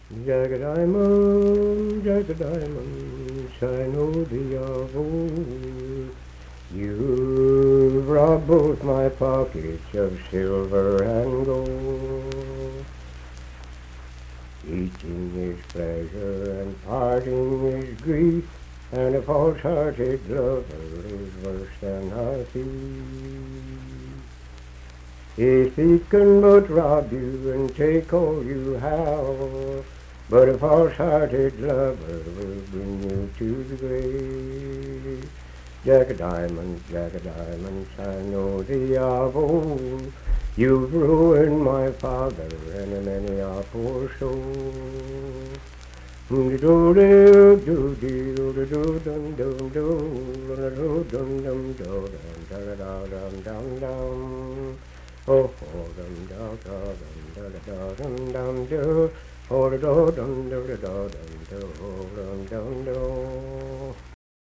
Unaccompanied vocal music performance
Verse-refrain 2d(4).
Voice (sung)